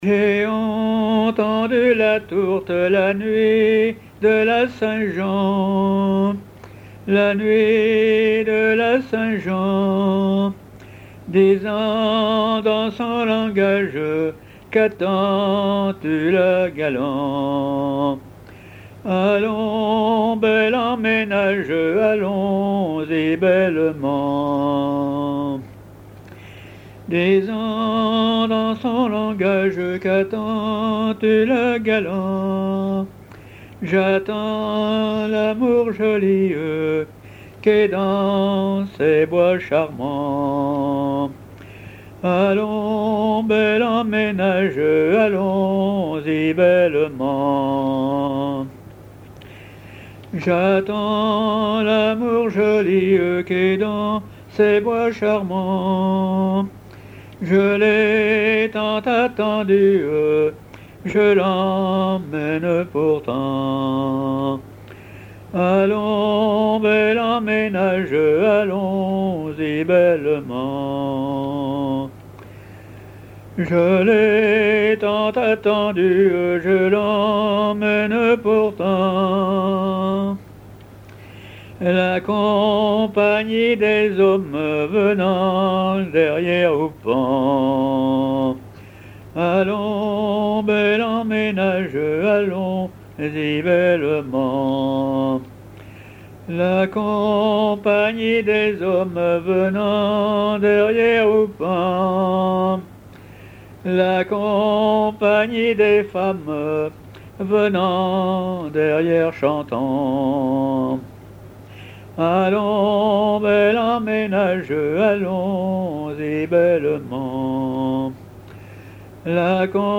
chansons à ripouner ou à répondre
Pièce musicale inédite